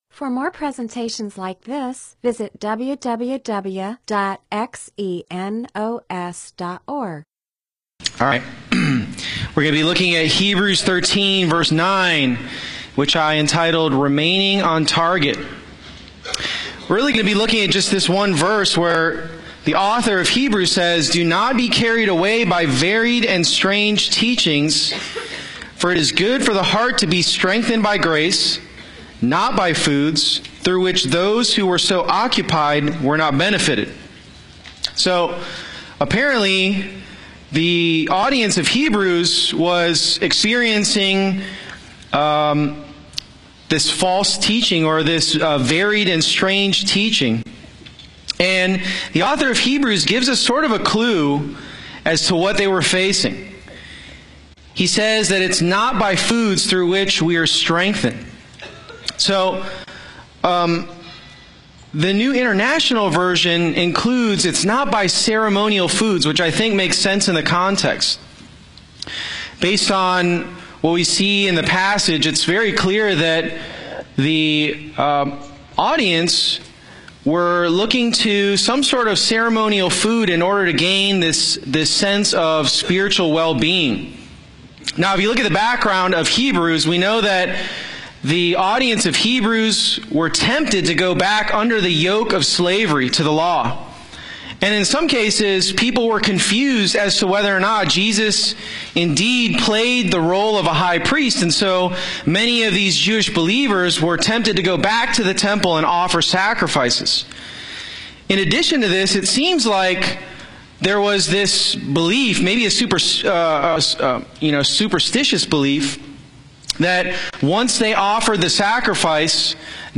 MP4/M4A audio recording of a Bible teaching/sermon/presentation about Hebrews 13:8-9.